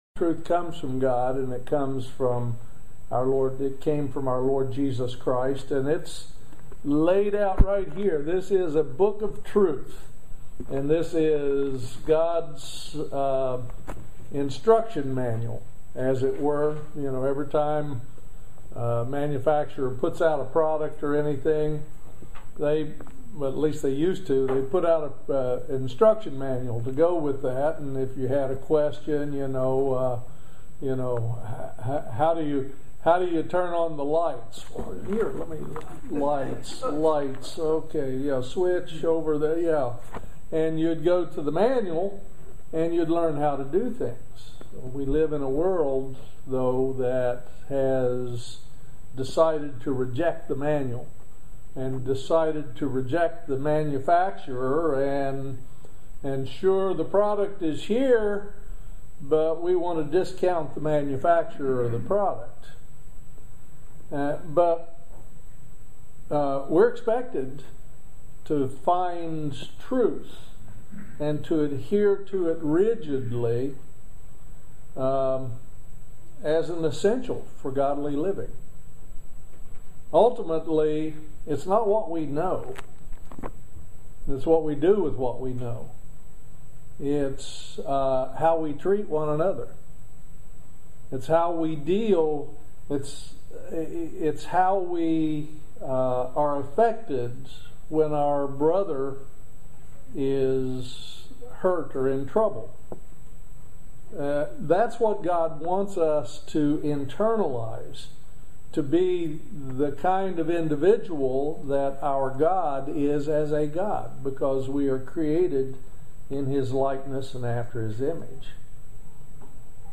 Join us for this excellent video sermon on Satan and his lies and deceptions.
Given in Lexington, KY